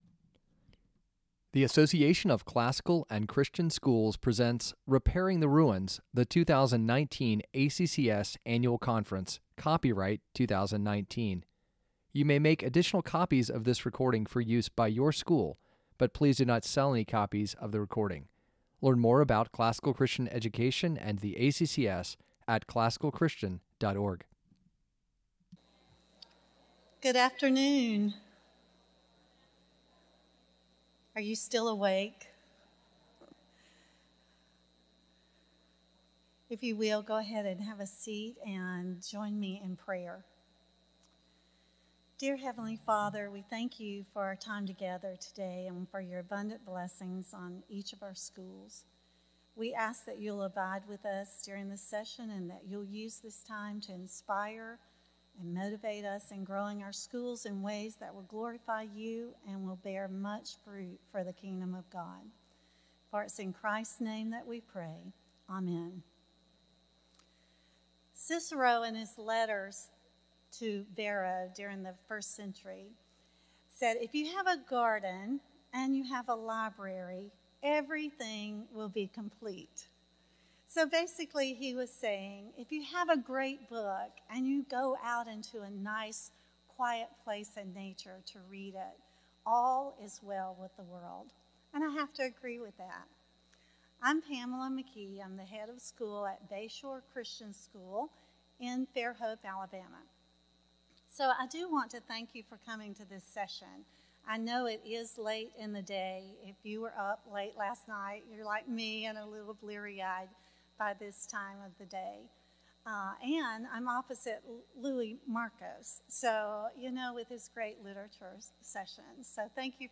2019 Workshop Talk | 01:00:14 | Fundraising & Development, Marketing & Growth